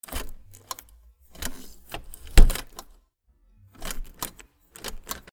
野外のドア
/ K｜フォーリー(開閉) / K05 ｜ドア(扉)
雑音多し NR 『シュインンゴ』